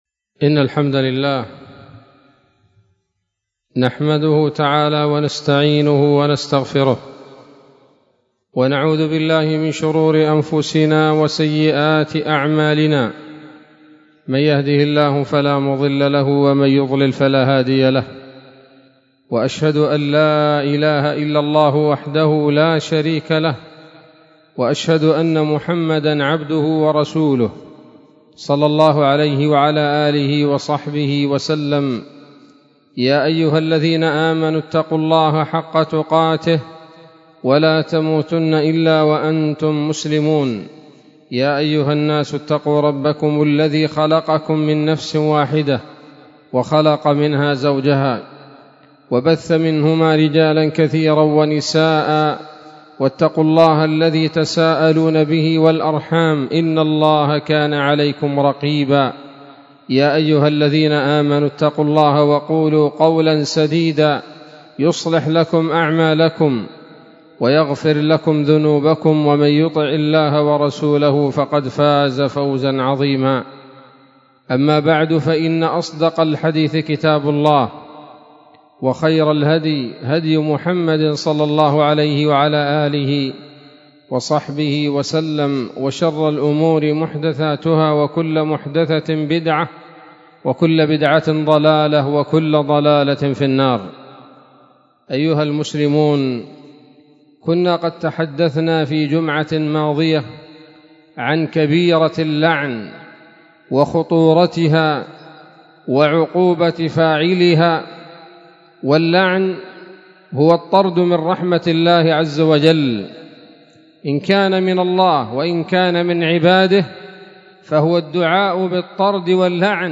خطبة جمعة بعنوان: (( الملعونون في القرآن الكريم )) 13 جمادى الآخرة 1444 هـ، دار الحديث السلفية بصلاح الدين